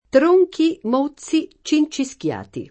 mozzo [m1ZZo] agg. («mozzato») — es.: crin mozzi [krim m1ZZi] (Dante); lettere mozze [l$ttere m1ZZe] (id.); e con acc. scr.: tronchi, mózzi, cincischiati [